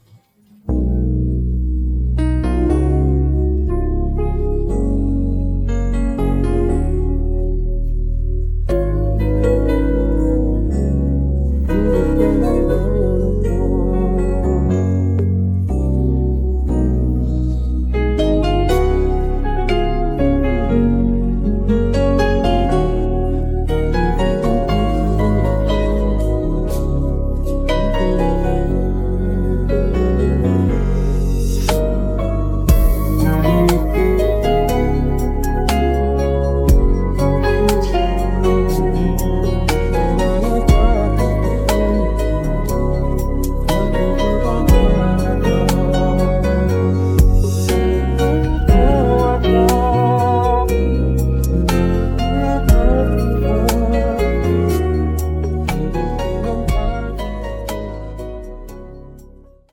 음정 -1키 3:29
장르 가요 구분 Voice MR